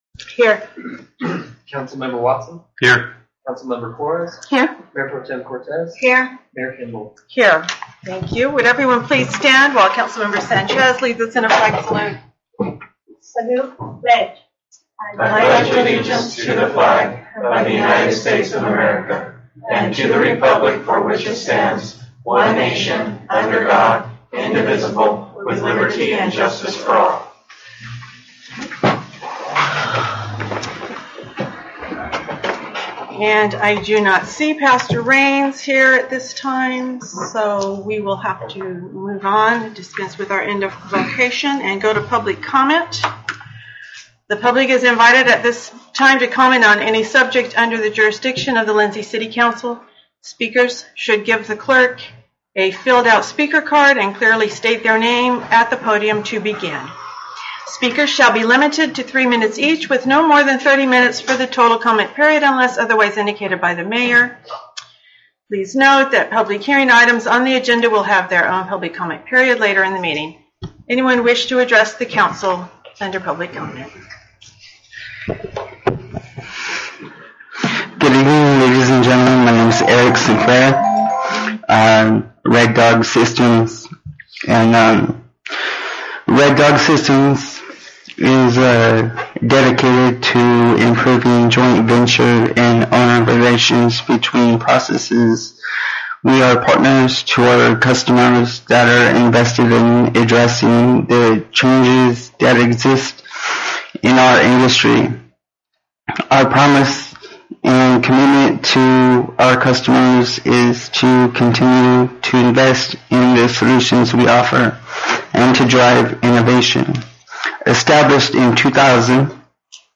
City Council Meeting